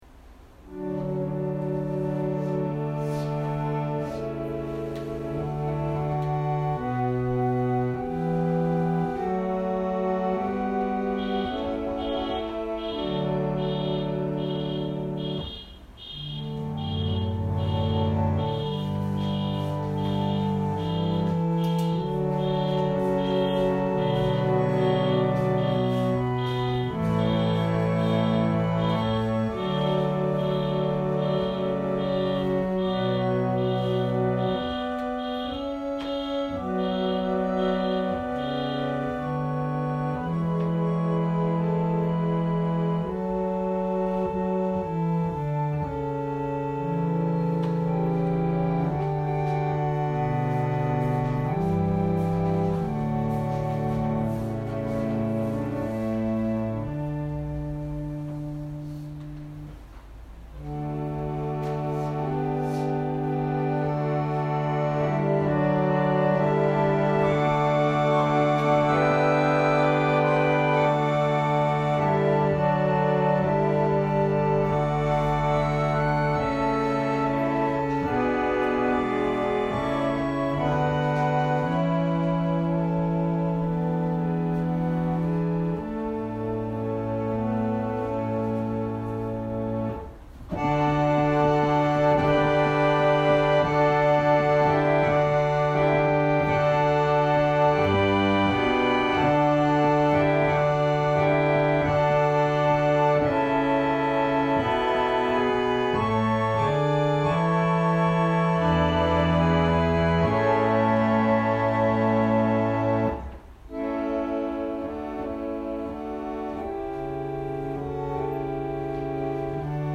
2019年12月15日朝の礼拝「ダビデの台頭 다윗의 대두(台頭)」せんげん台教会
音声ファイル 礼拝説教を録音した音声ファイルを公開しています。